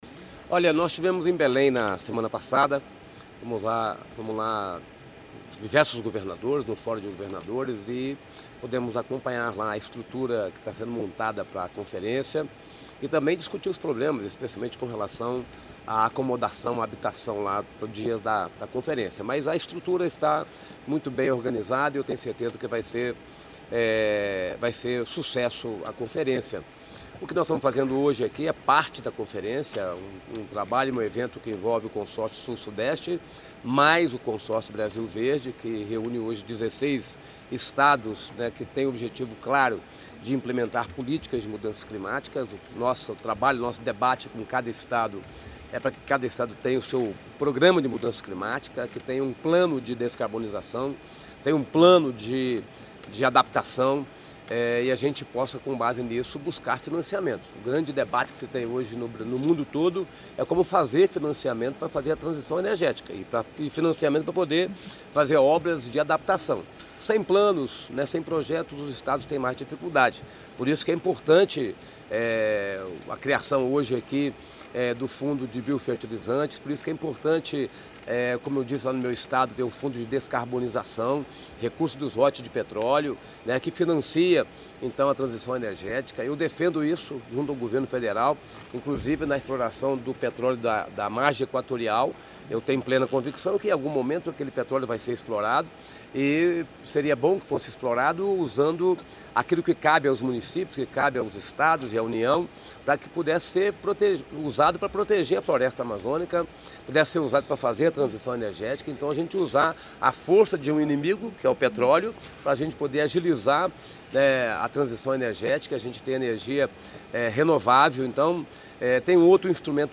Sonora do governador do Espírito Santo, Renato Casagrande, sobre a 13ª edição do Cosud